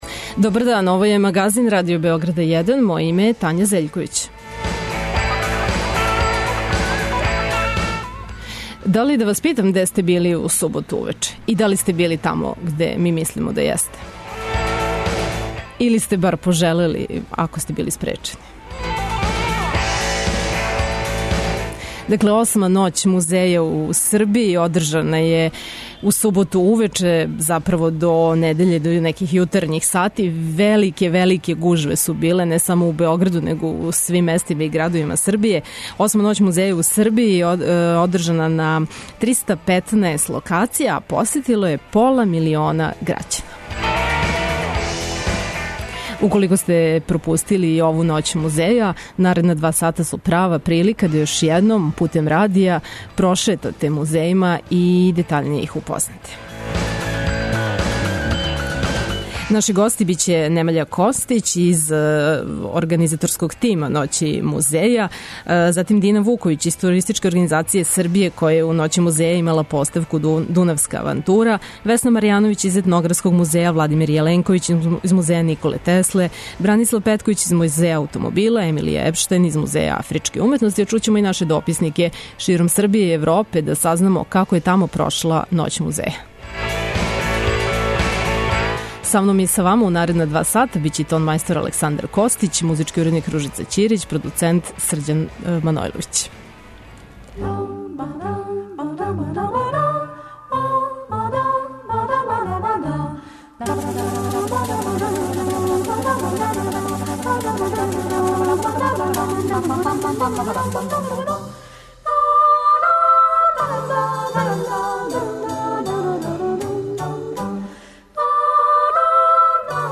Како је било, говориће организатори, аутори музејских поставки, дописници РТС-а из читаве Србије и већих европских градова, као и посетиоци.